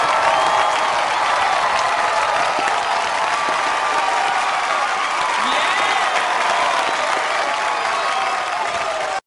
Categories: Sound Effect